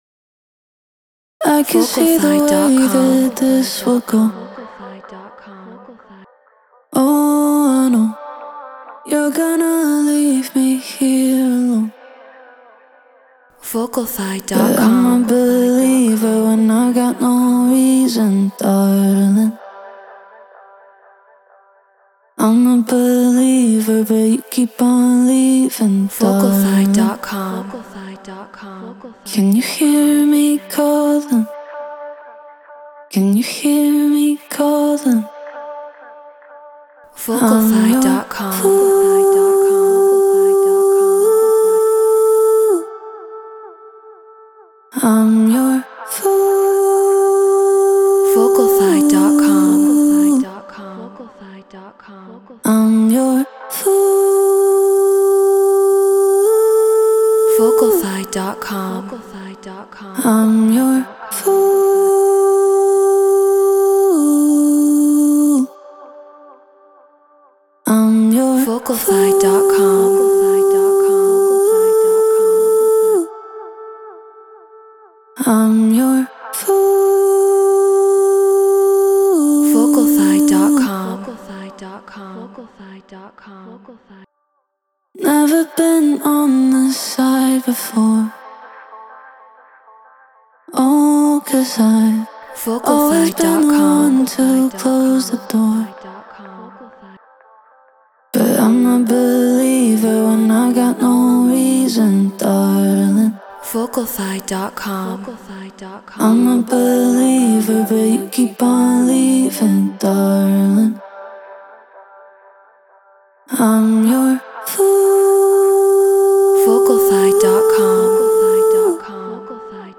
Stutter House 126 BPM Amin
Shure SM7B
Treated Room